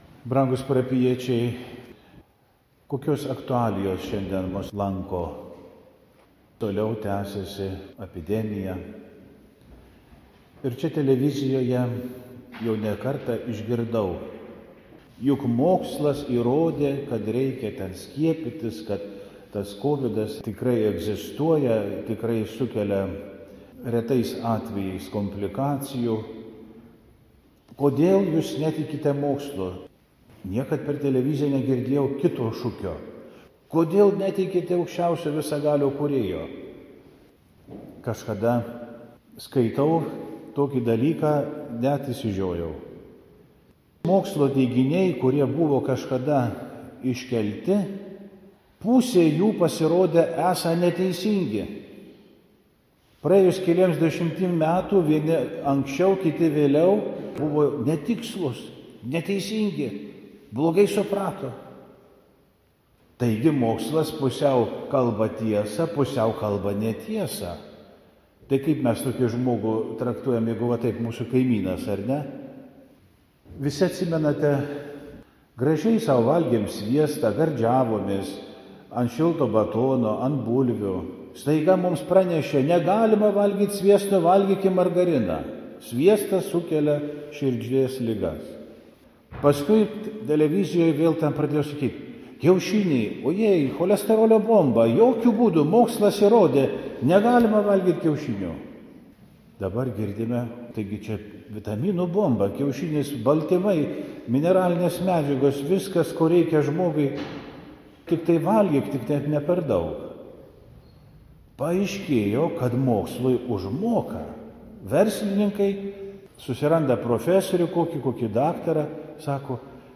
Pamokslai